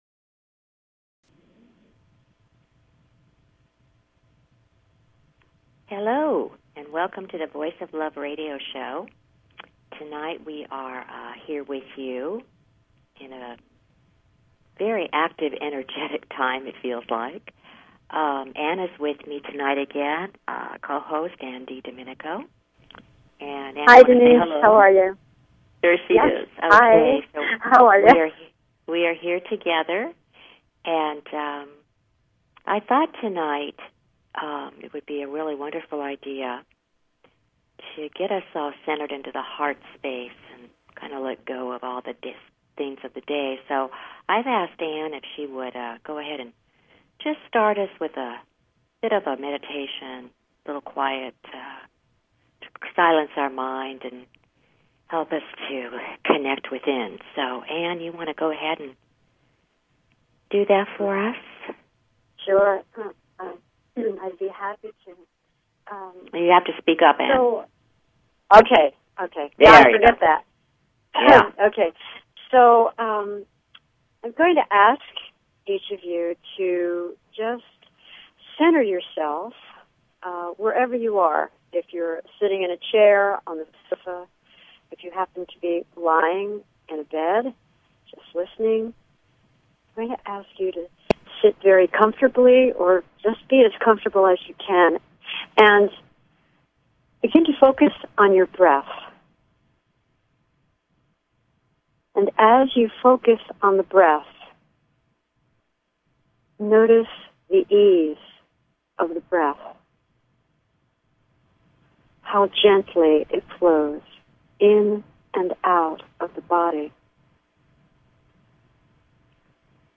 Talk Show Episode, Audio Podcast, The_Voice_Of_Love and Courtesy of BBS Radio on , show guests , about , categorized as